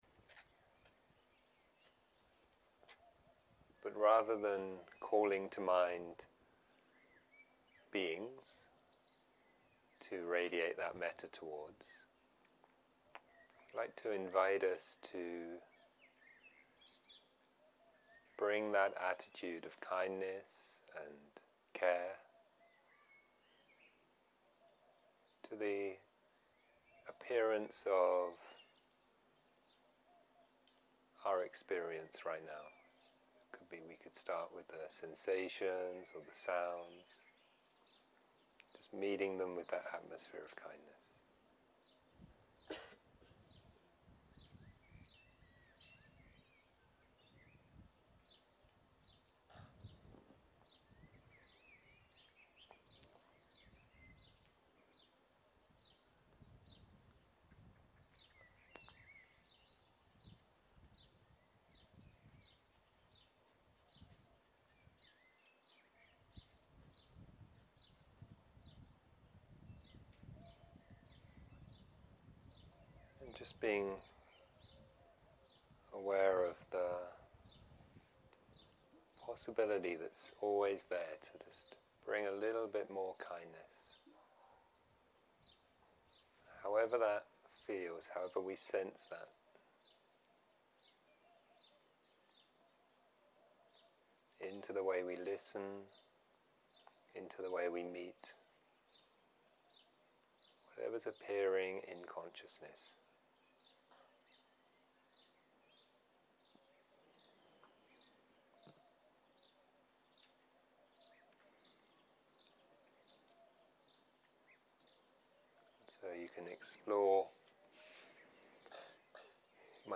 צהריים - מדיטציה מונחית - מטא לכל התופעות
סוג ההקלטה: מדיטציה מונחית